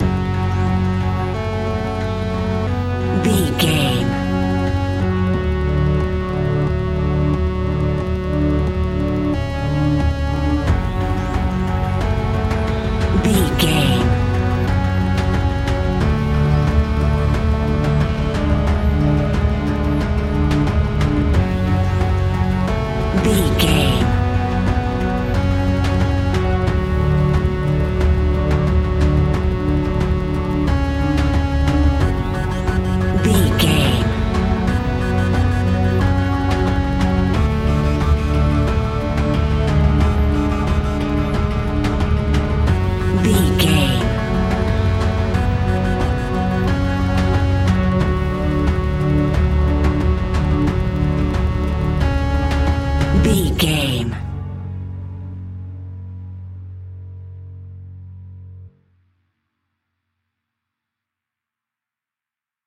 Aeolian/Minor
F#
ominous
dark
eerie
industrial
drums
synthesiser
horror music